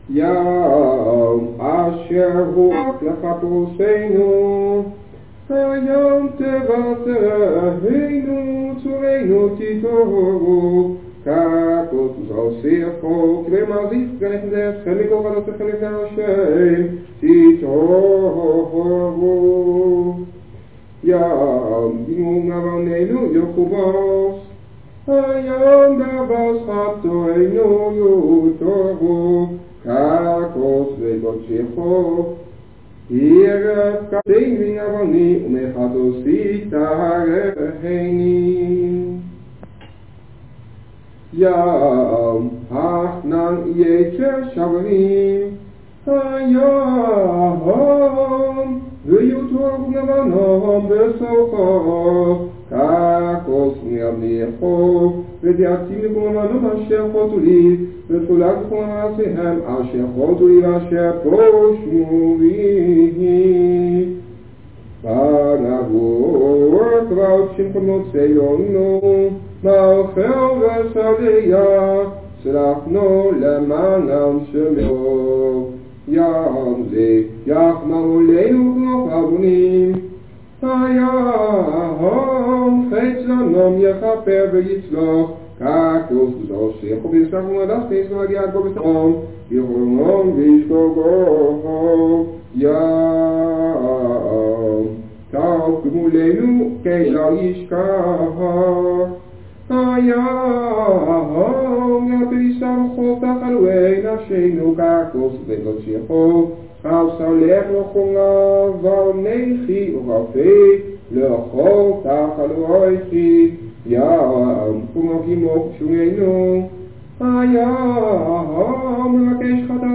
op cassettebandjes